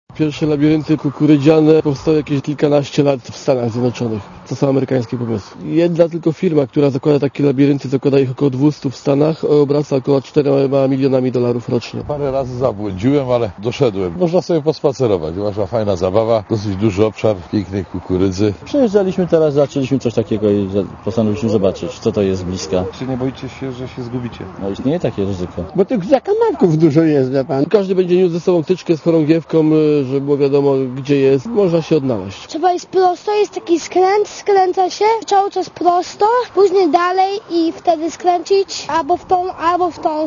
Pierwszy w Polsce i jednocześnie największy w Europie labirynt w kukurydzy otwarto w Brzozowie w Zachodniopomorskiem.